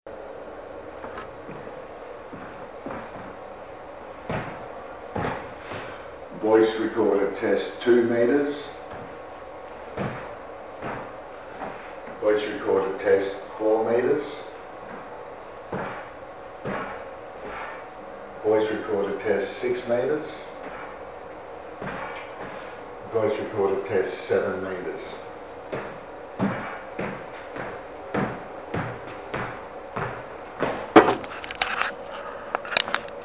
AFOTOP10-Encrypted-Voice-Recorder-with-password-Sample-Audio.mp3